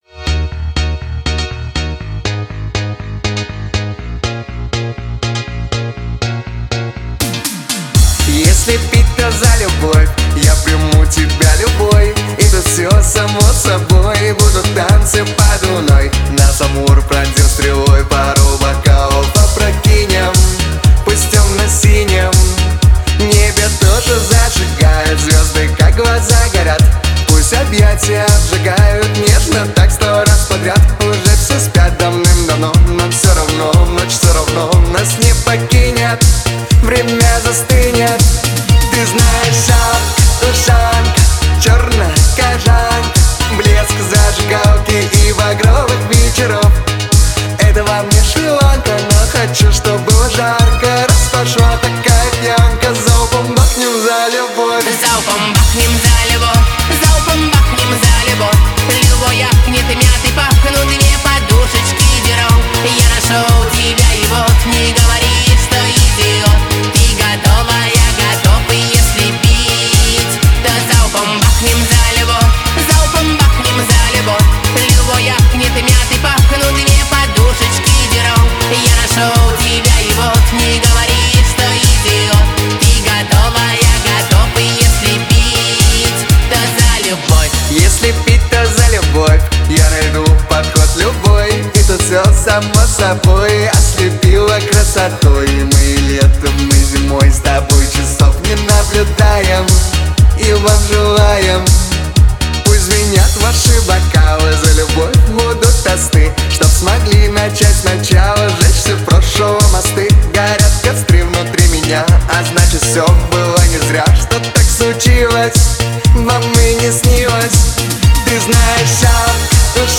Танцевальная музыка
танцевальные песни
dance песни